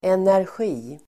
Uttal: [enärsj'i:]